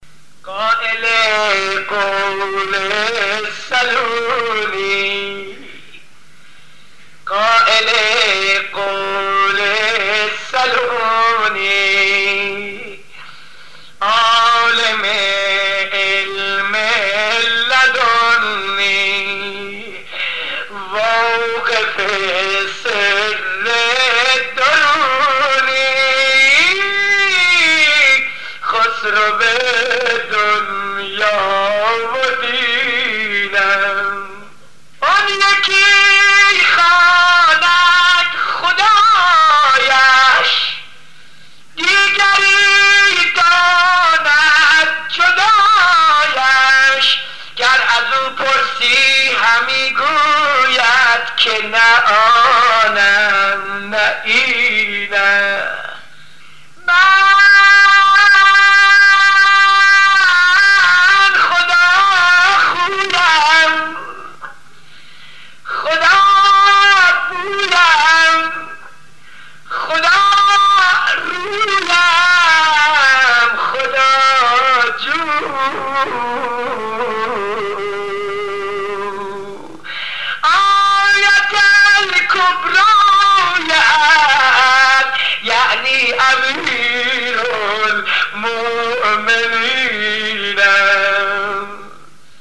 منقبت‌خوانی در وصف مولا علی(ع)
مشهور است که در «مسجد ارک» تهران اجرا شده است.